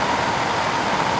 blowloop.mp3